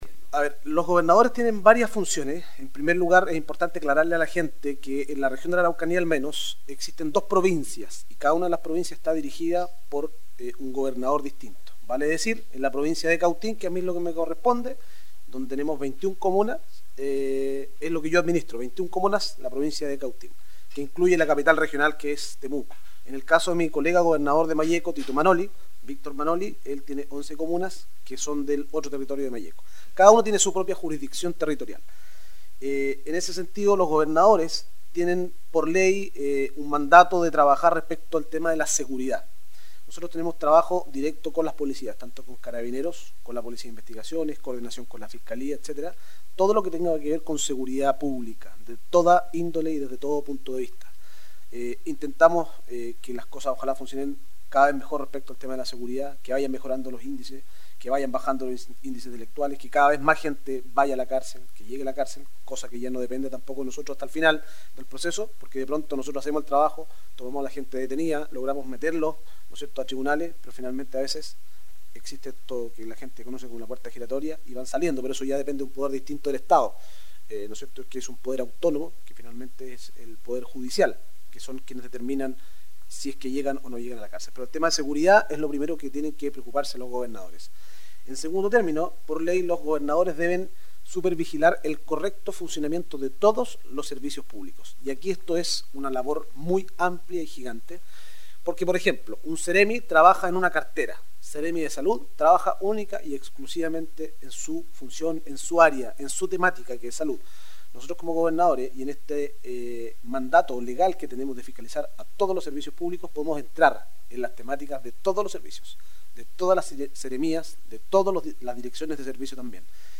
En nuestra novena entrevista de este proyecto nos dirigimos hasta la Gobernación de Cautín para entrevistar a Mauricio Ojeda Gobernador de esta provincia.